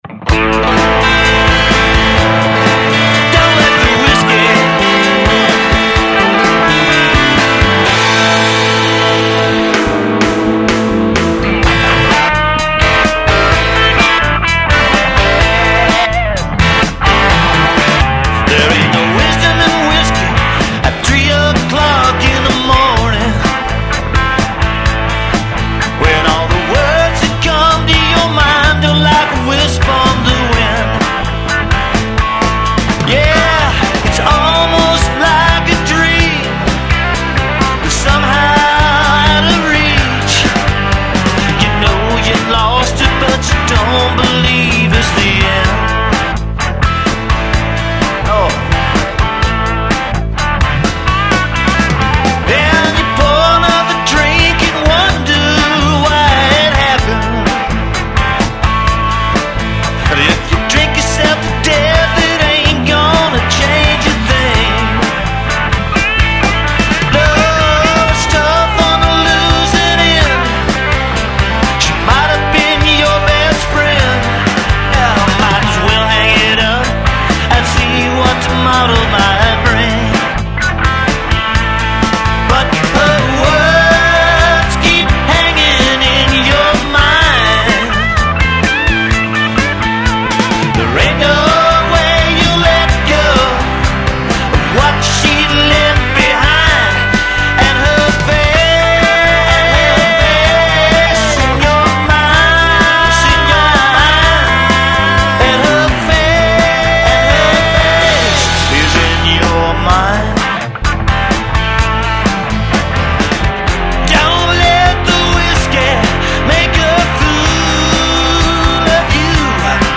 guitars, keys, vocals, harmonica, programming
guitars, bass, vocals